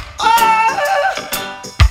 lively-ringtone.wav